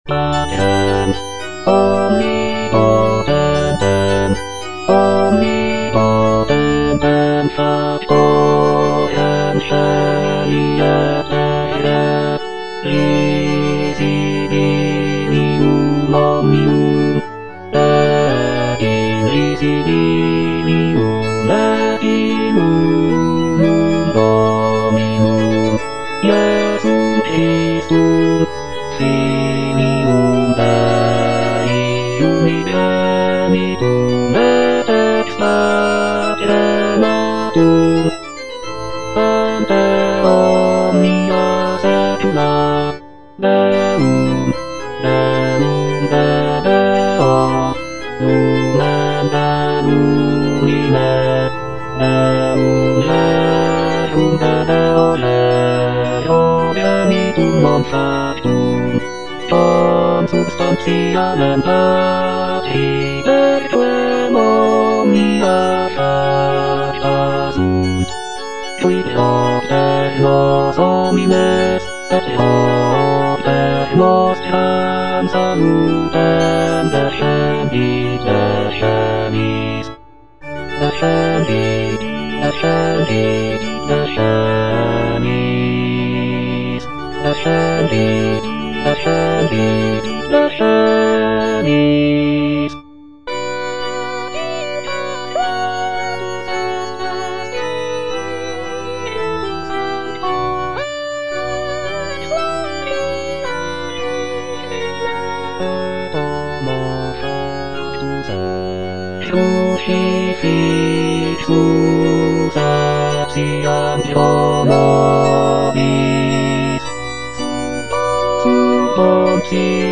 W.A. MOZART - MISSA BREVIS KV194 Credo - Bass (Emphasised voice and other voices) Ads stop: auto-stop